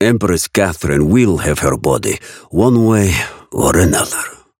Raven voice line - Empress Catherine will have her body, one way or another.